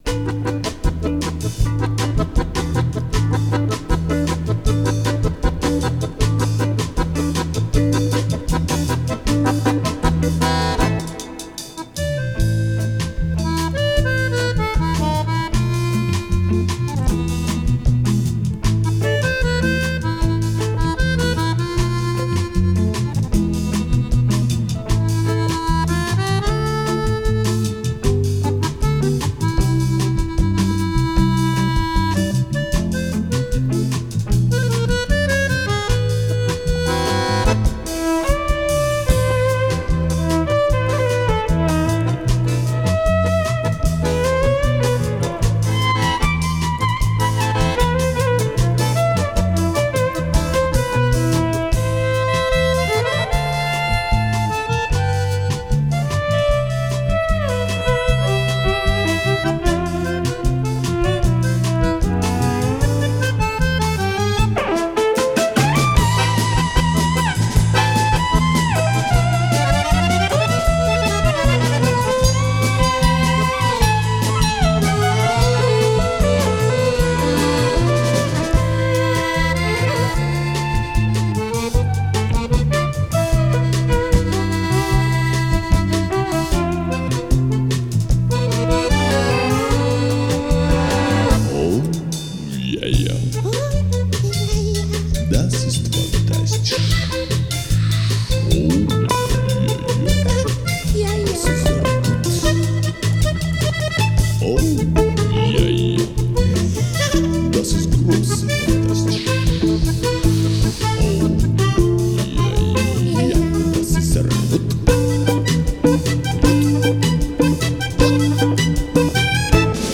скрипка, вокал.
бас, вокал.
аккордеон, ф-но, вокал.
ударные.